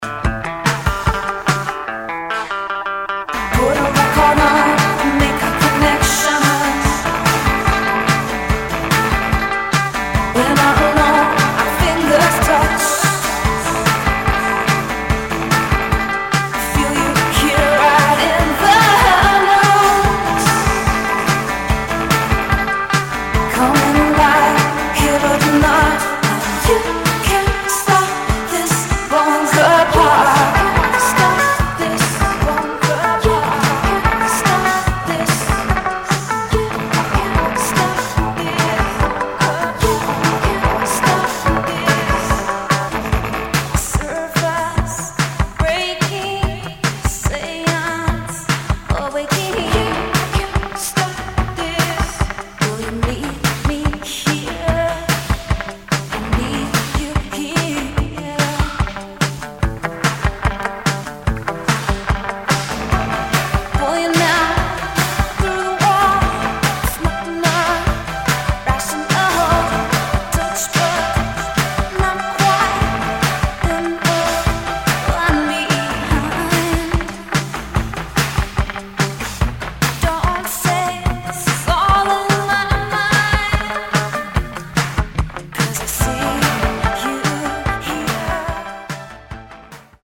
NO WAVE | ELECTRONIC | EXPERIMENTAL